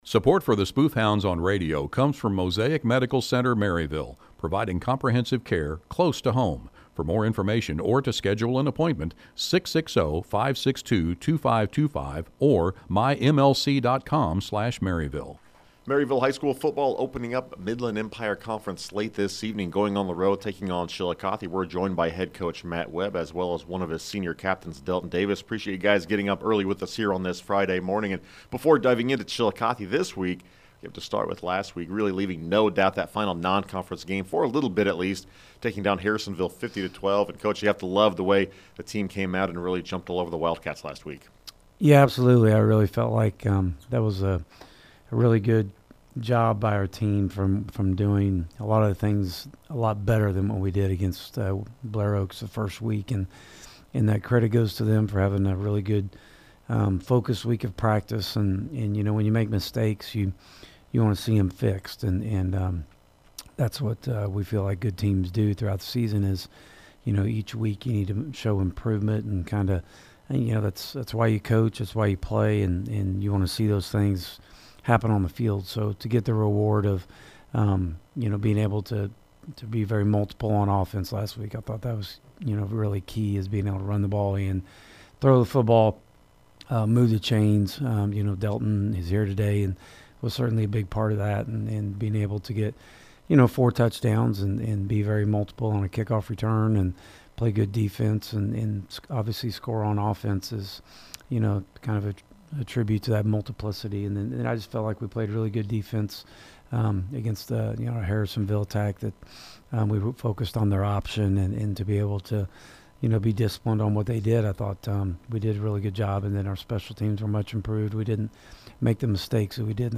Local Sports